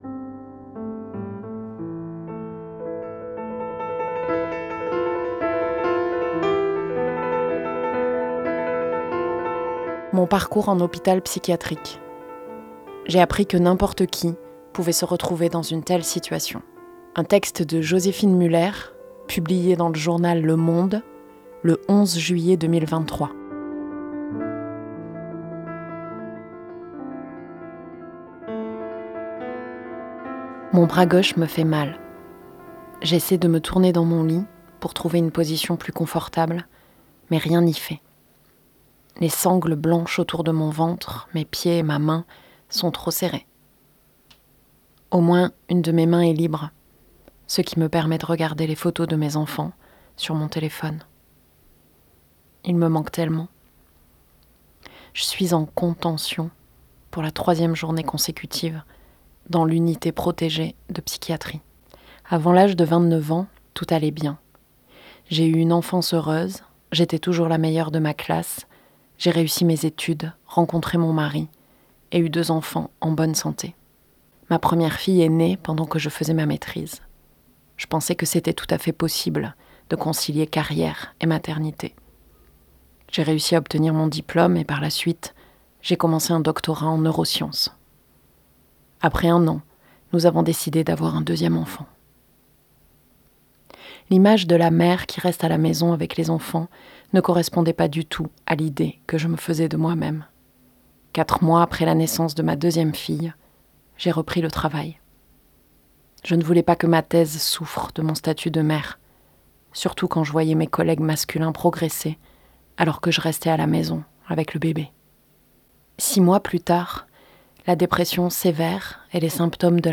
Lecture :